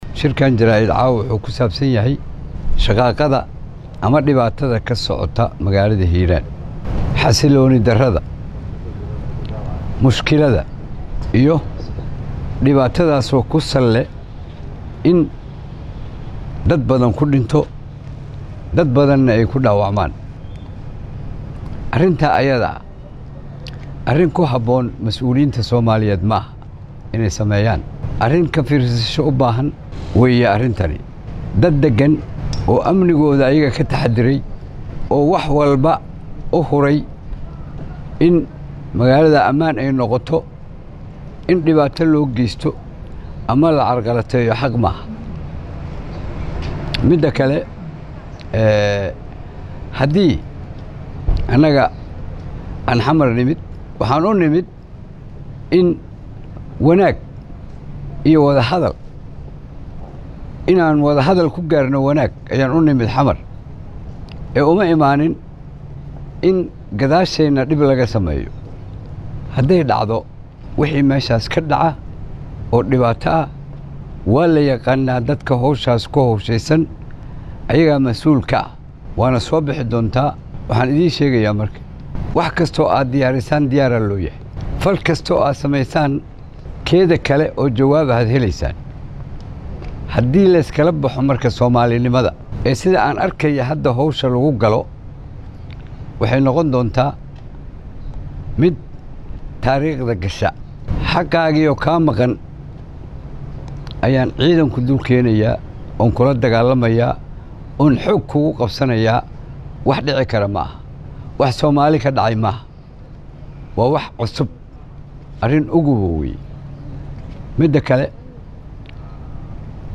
oo xalay Shir Jaraa’id ku qabtay Magaalada Muqdisho ee caasimadda dalka